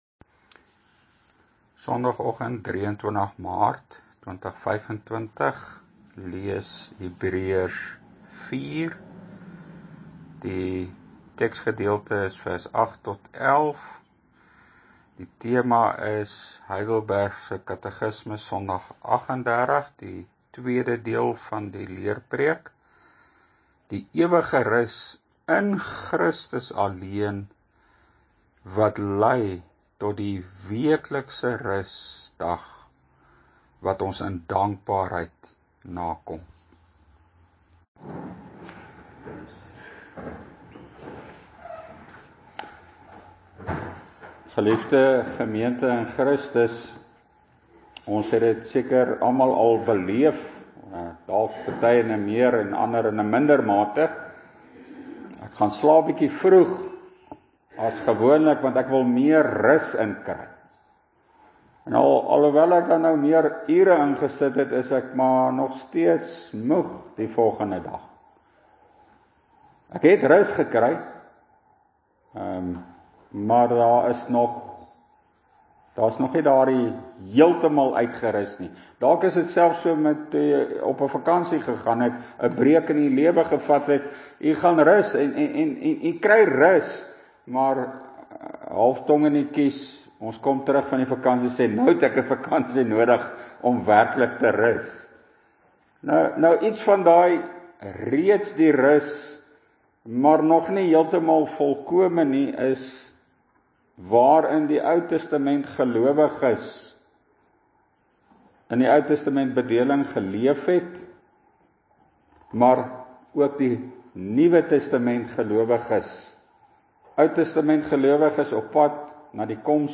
Die ewige sabbatsrus in Christus, lei tot die eerste dag van die week sabbatsrus elke week: twee leerpreke oor HK Sondag 38 – die vierde gebod, ook vir vandag!
(GK Carletonville, 16 Maart 2025)